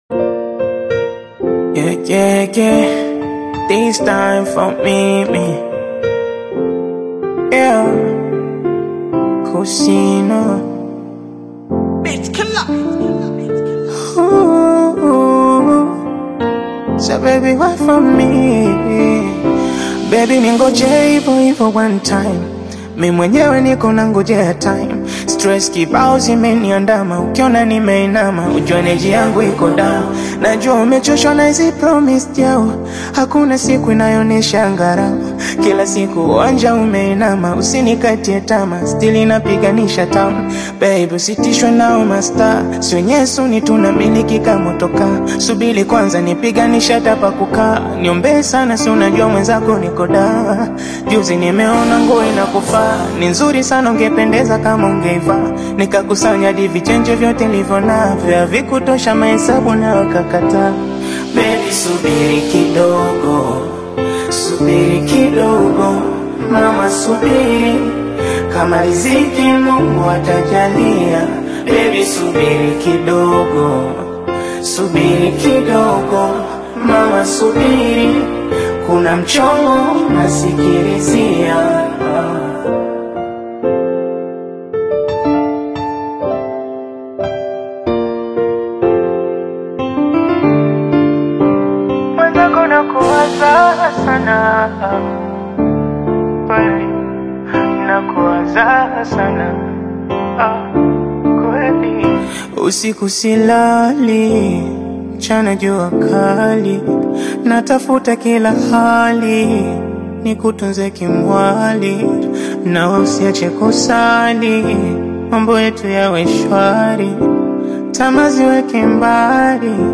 catchy rhythm